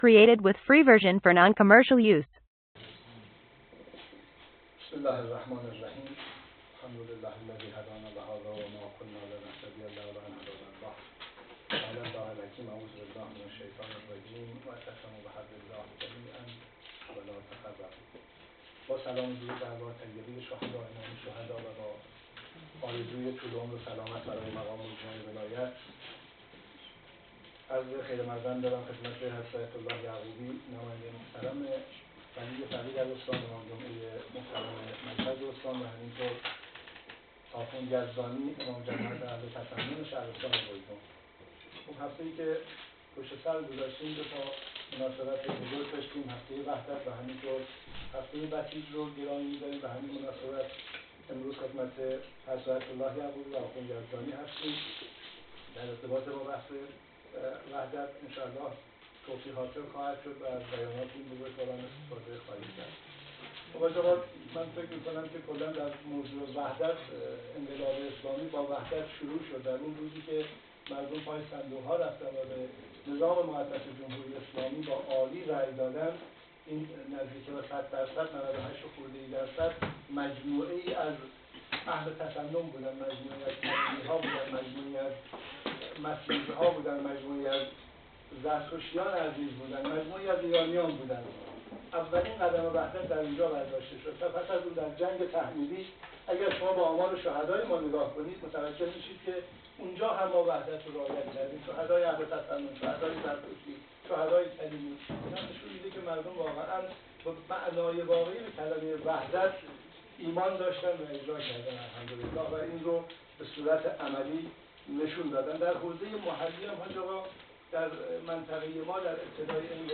شصت و ششمین جلسه شورای اسلامی شهر بجنورد برگزار شد.
فایل صوتی جلسه: